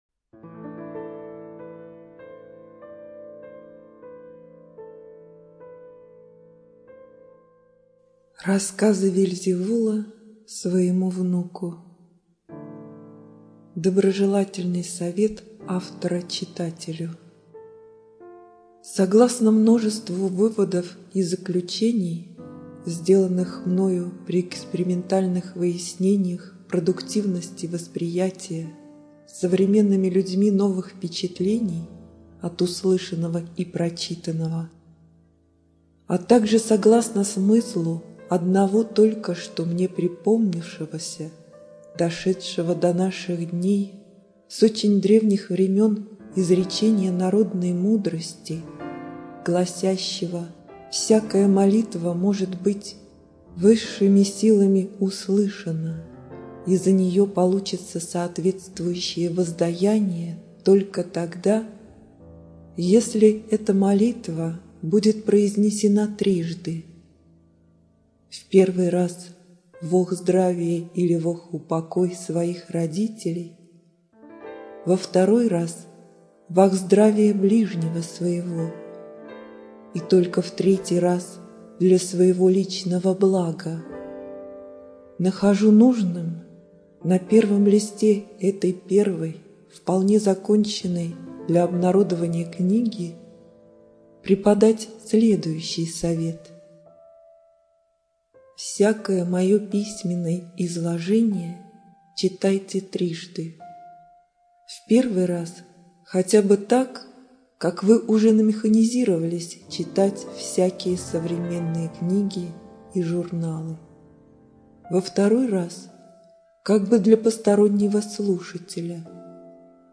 ЖанрЭзотерика